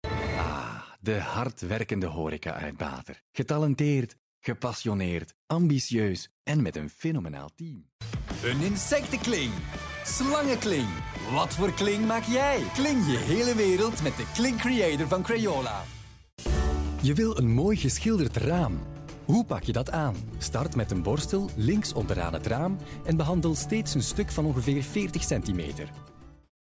Professionelle Sprecher und Sprecherinnen
Belgisch
Männlich